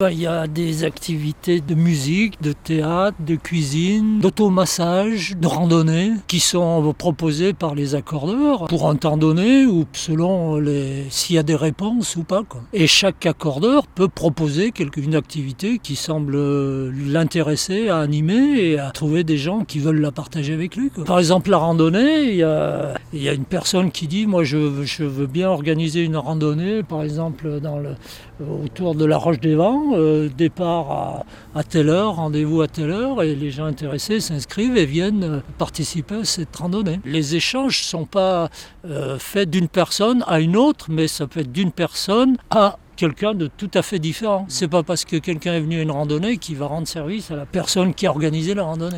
Ce retraité évoque les différentes activités qui sont proposées à travers cette structure.